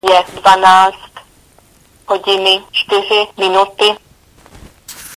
Mluvící hodiny v telefonech Nokia
neskloňuje správně, je hlášení času přirozeným ženským hlasem dobře srozumitelné.